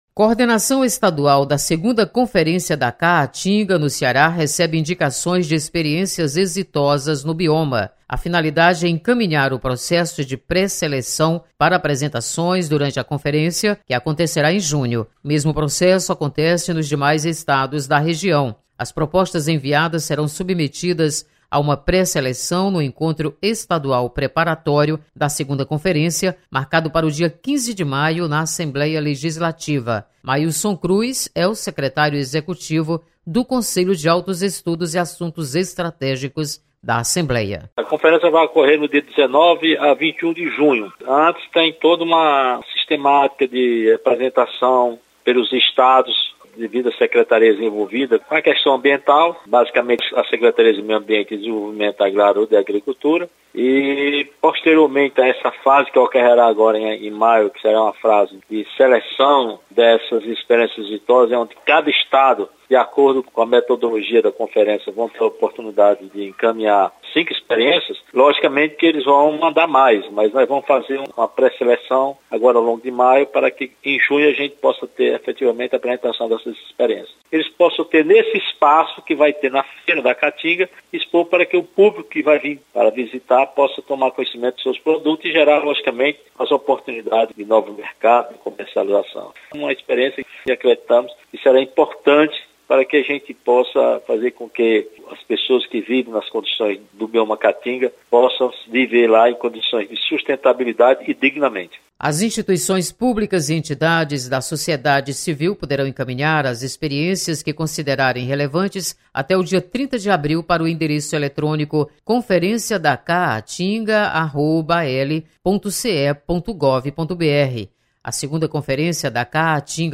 Conselho recebe experiências exitosas para conferência sobre caatinga. Repórter